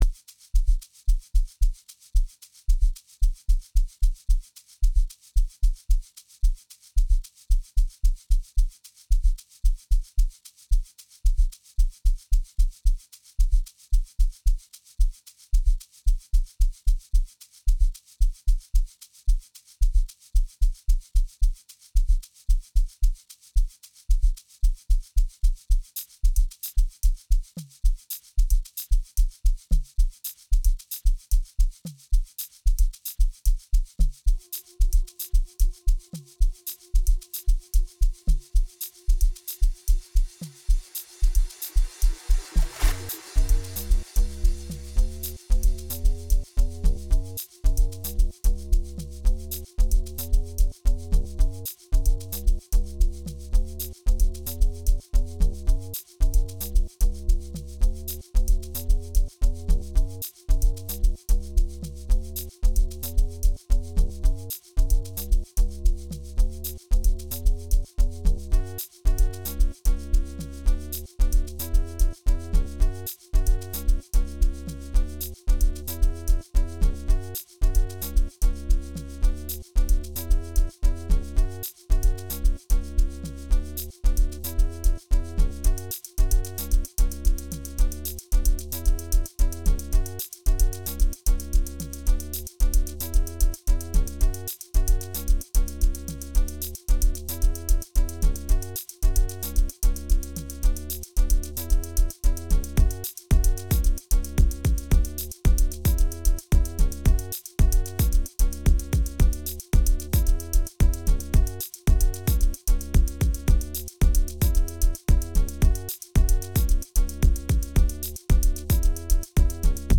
06:59 Genre : Amapiano Size